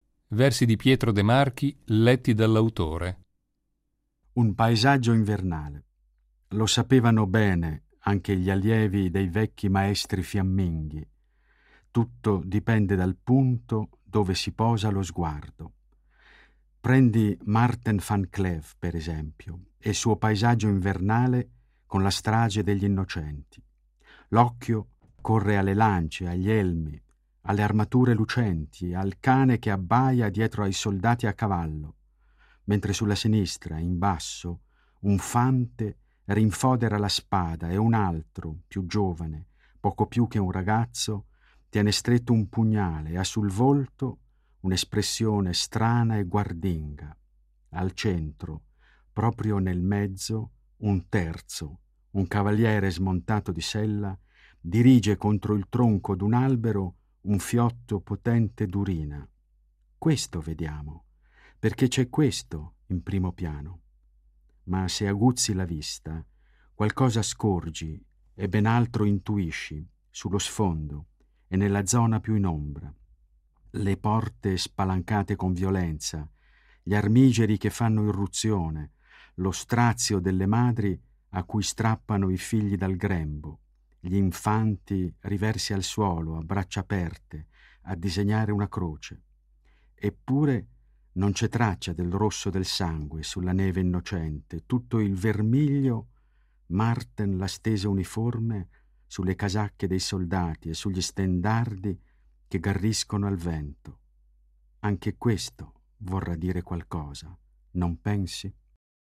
Poeti della Svizzera italiana leggono i propri testi